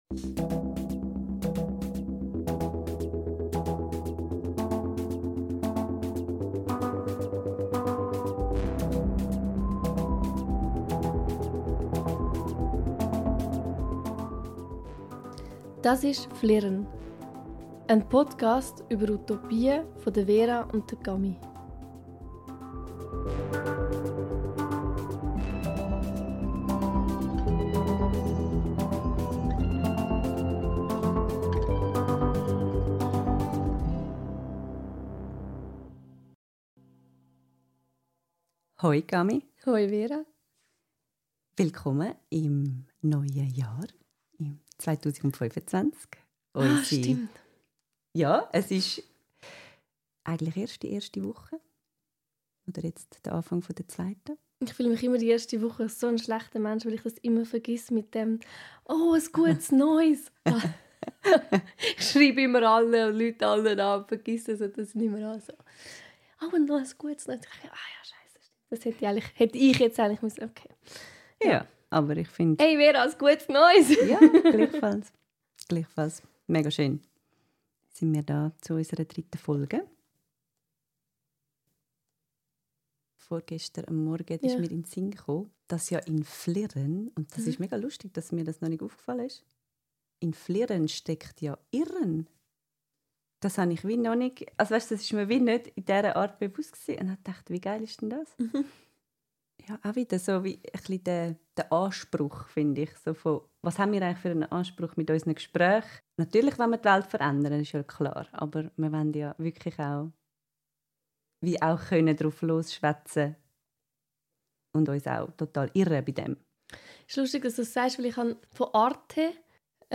Hört selbst, wie wir das alles zu einem Gespräch über Gemeinschaft und Utopie verweben und welche Brücken wir über welche Gedankenströme bauen.